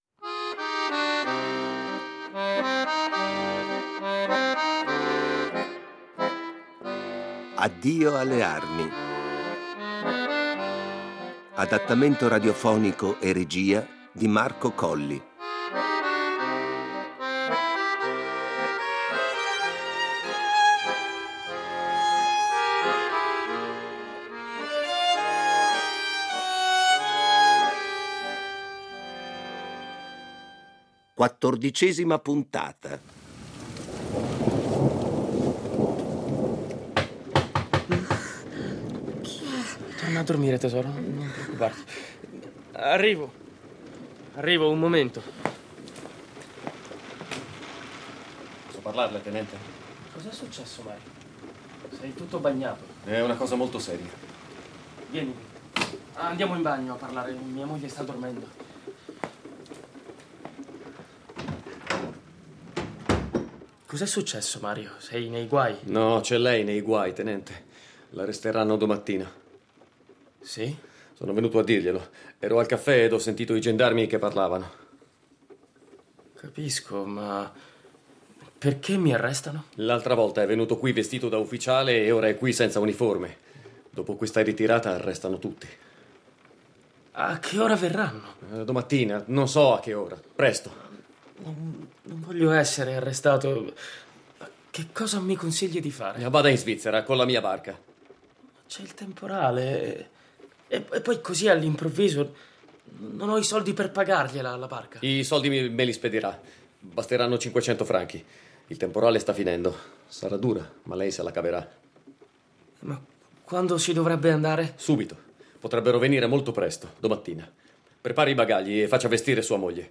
Riduzione, adattamento radiofonico e regia